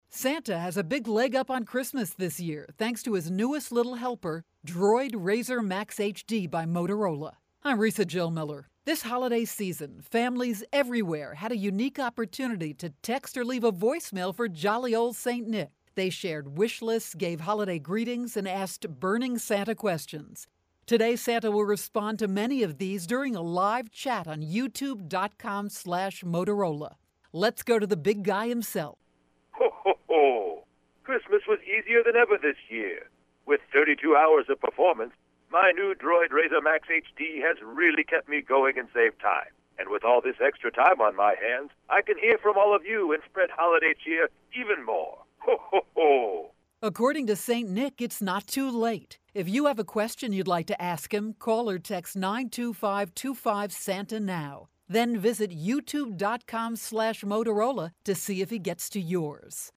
December 21, 2012Posted in: Audio News Release